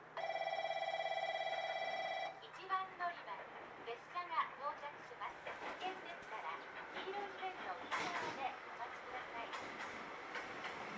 この駅では接近放送が設置されています。
接近放送普通　国分行き接近放送です。